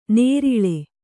♪ nēriḷe